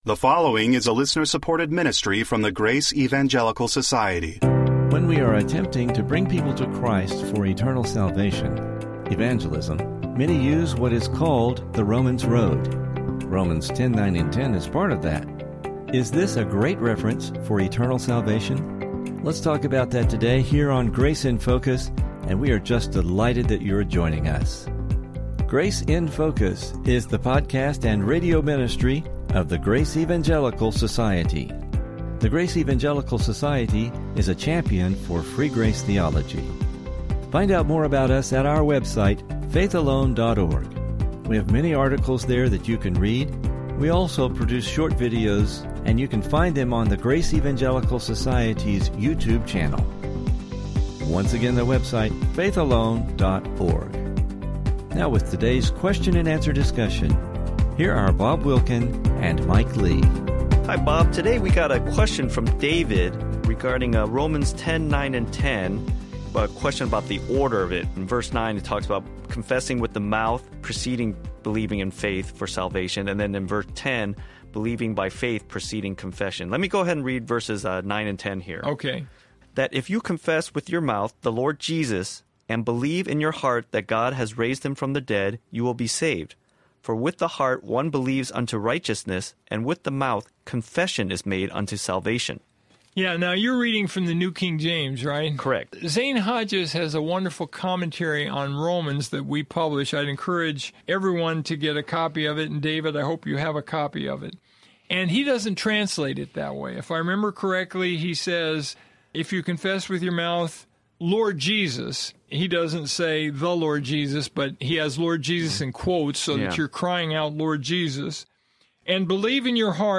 Please listen for an interesting Biblical discussion regarding this passage!